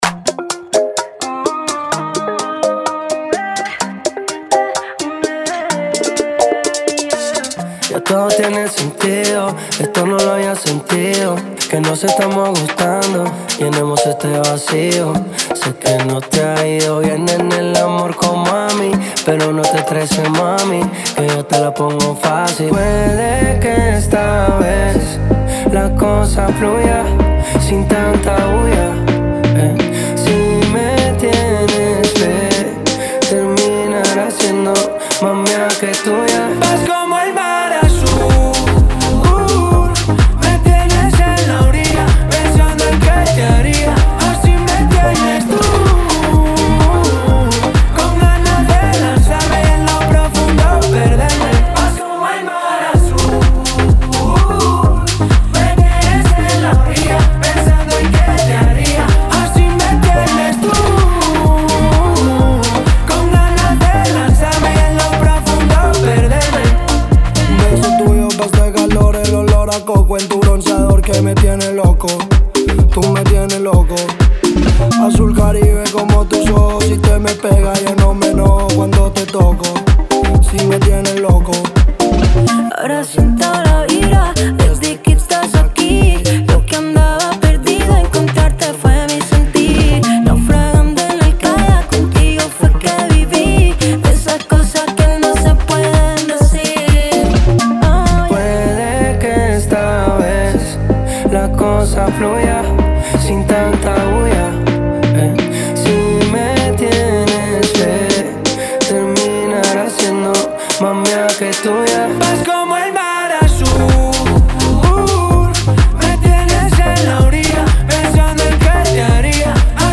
Latin Pop